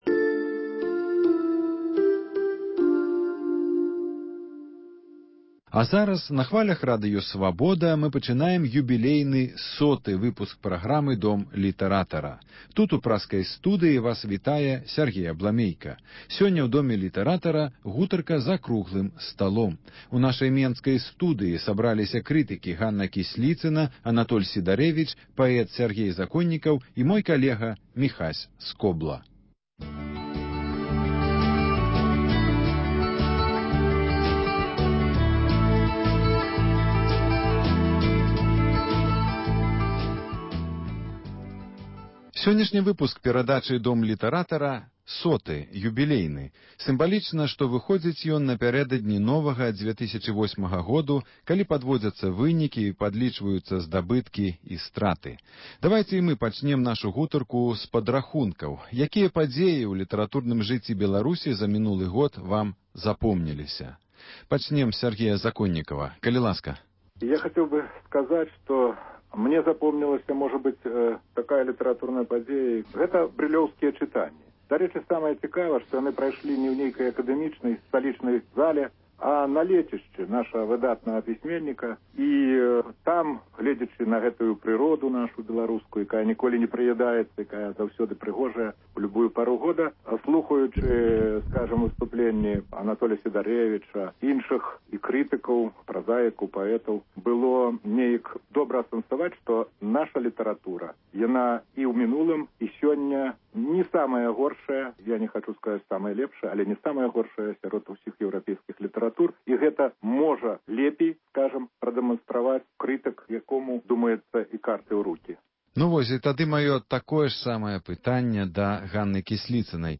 круглы стол